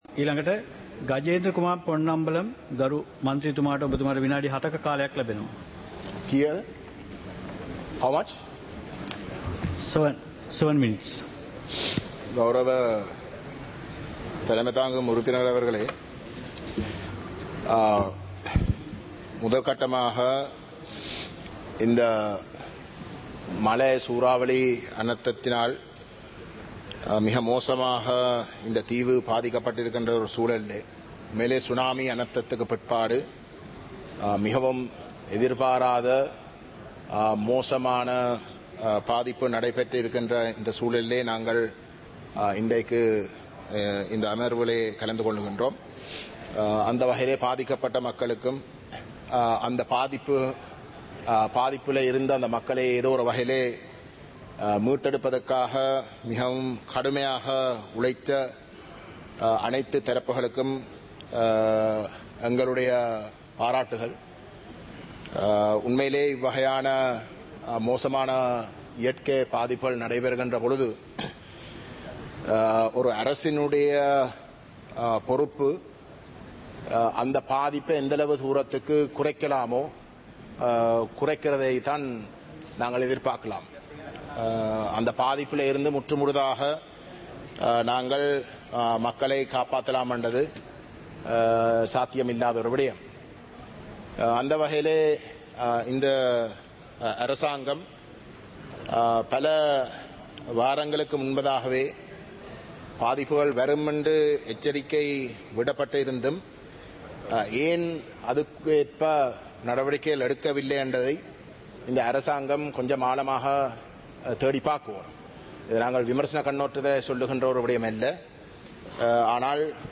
இலங்கை பாராளுமன்றம் - சபை நடவடிக்கைமுறை (2025-12-05)